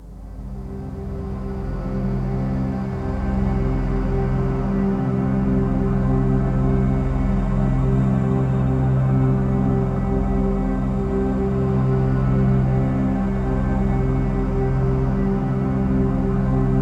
ATMOPAD01 -LR.wav